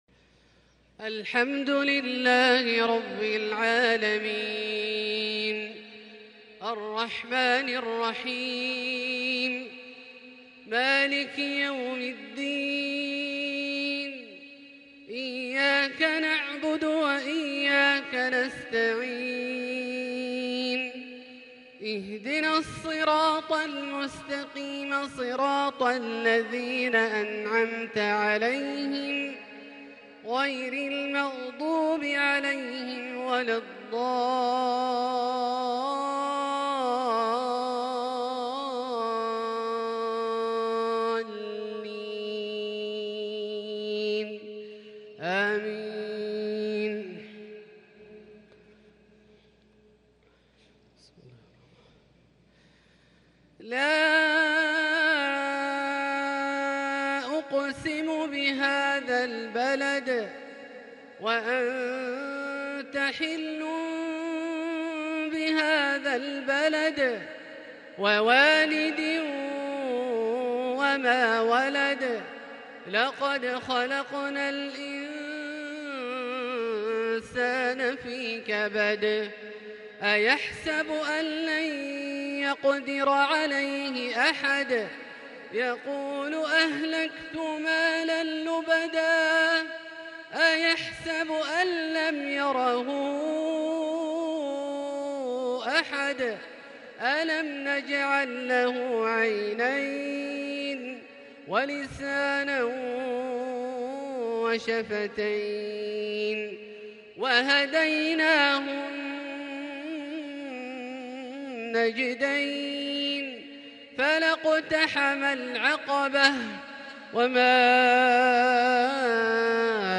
عشاء الثلاثاء 6 شوال 1442هــ سورتي البلد و الشمس > ١٤٤٢ هـ > الفروض - تلاوات عبدالله الجهني